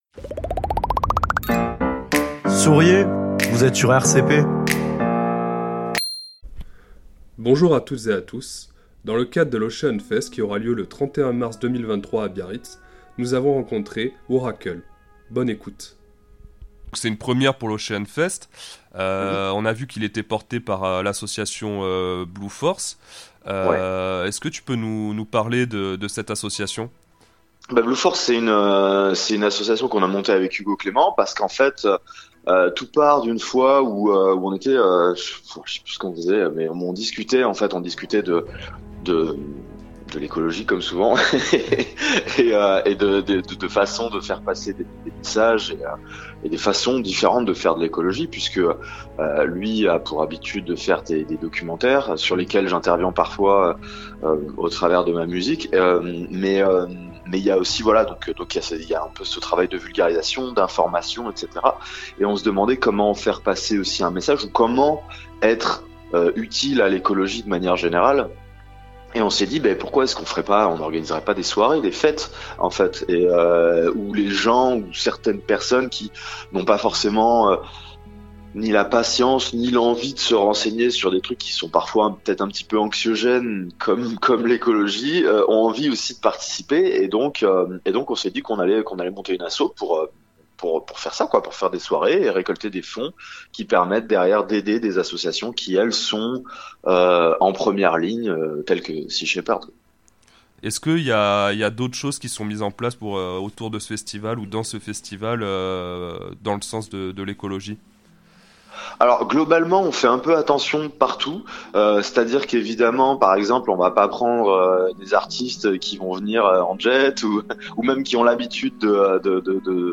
Interview de Worakls pour l'Ocean Fest